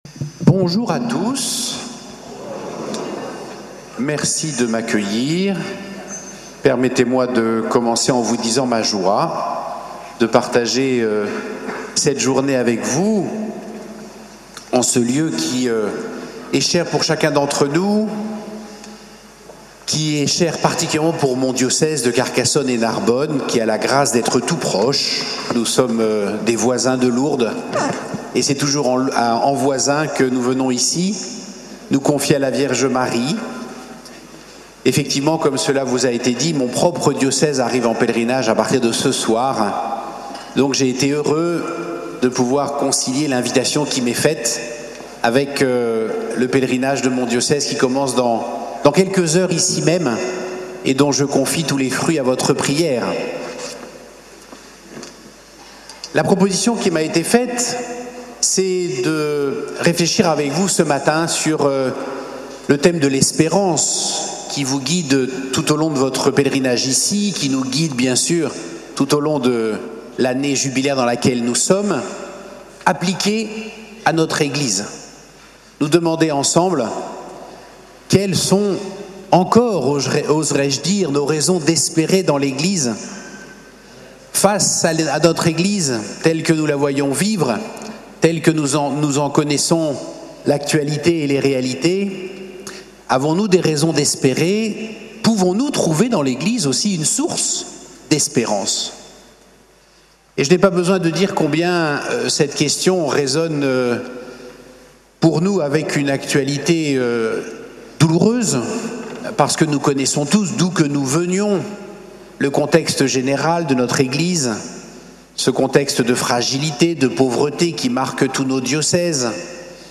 Lourdes, Pèlerinage avec la Cté des Béatitudes avec Mgr Bruno Valentin